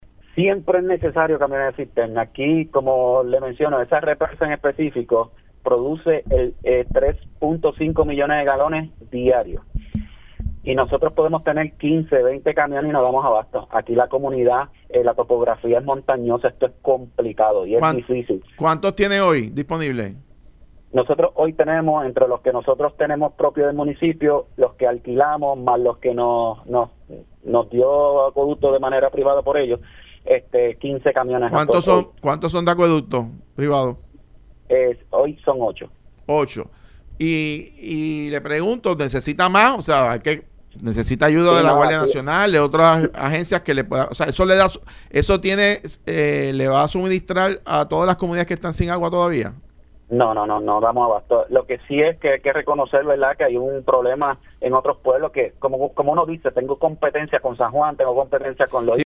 El alcalde de Corozal, Luis ‘Luiggi’ García insistió en Los Colbergs que su pueblo no da abasto con los camiones de cisterna durante la crisis de agua de la Autoridad de Acueductos y Alcantarillados (AAA).